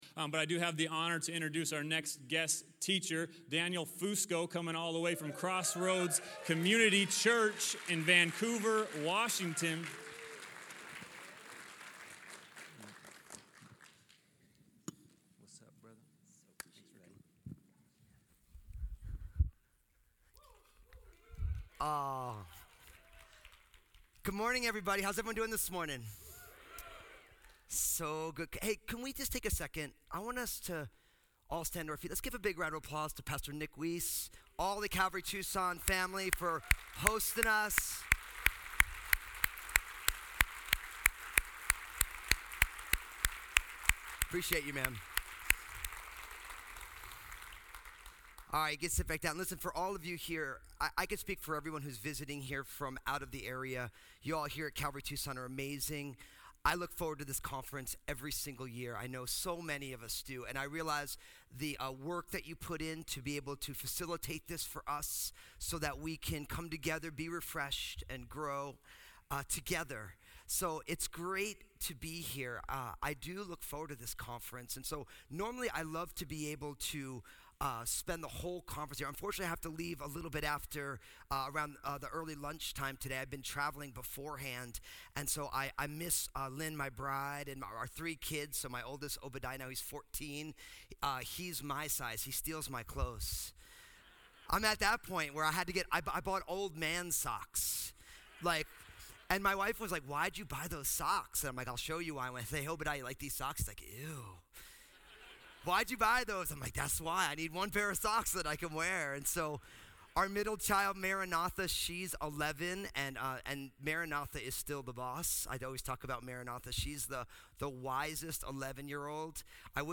Southwest Pastors and Leaders Conference 2019